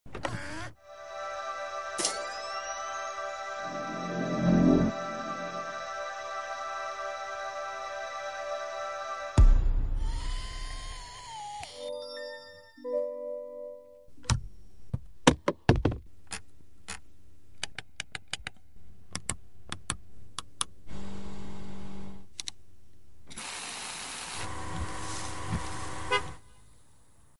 ( $67,500 ) ASMR CADILLAC sound effects free download